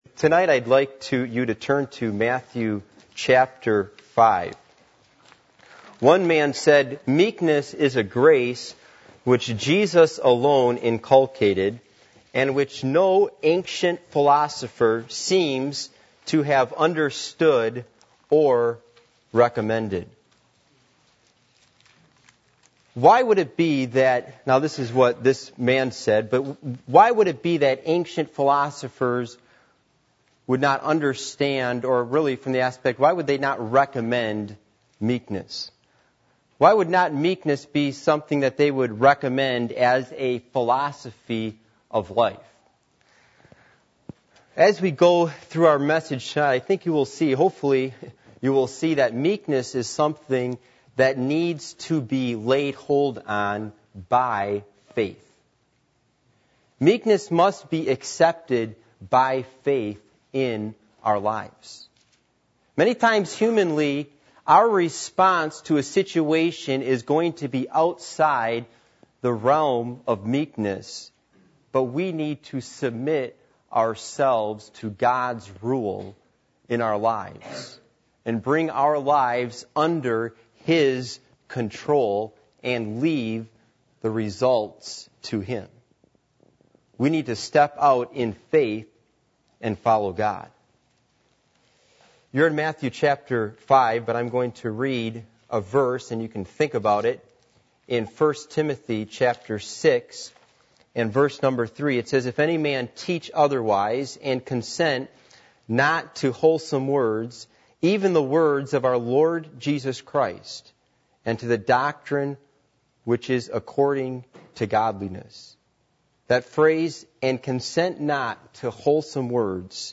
Passage: Matthew 5:5 Service Type: Sunday Evening